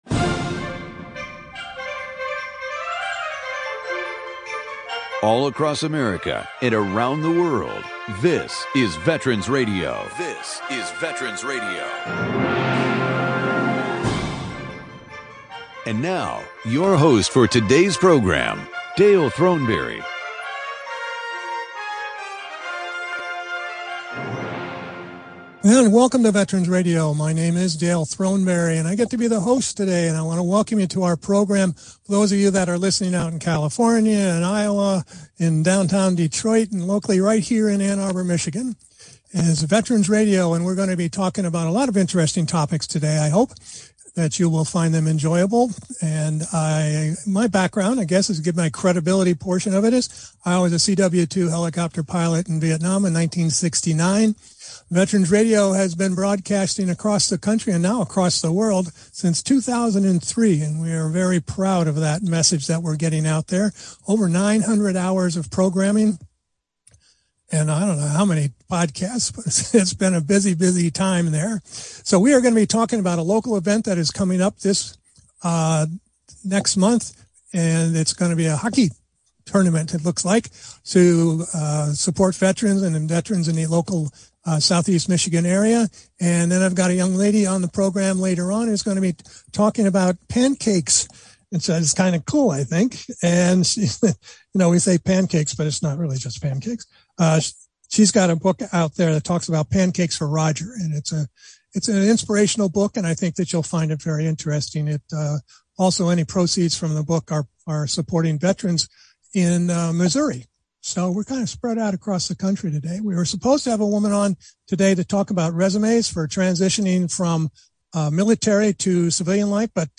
Our radio broadcast with host